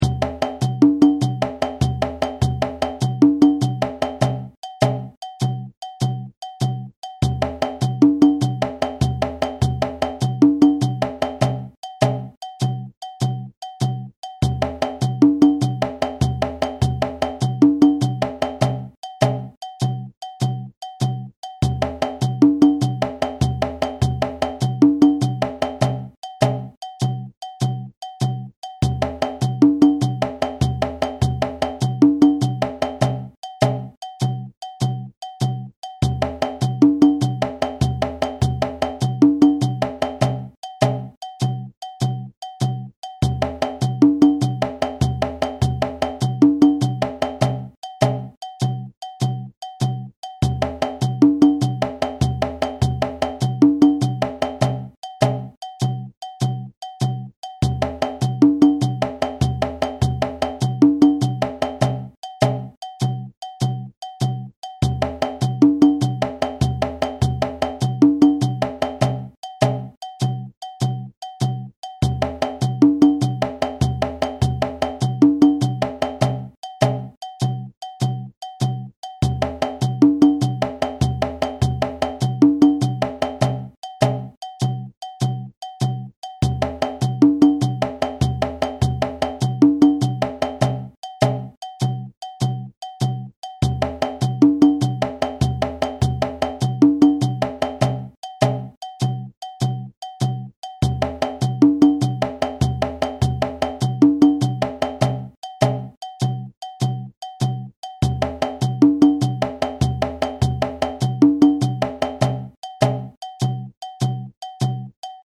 audio (with shekeré & bell)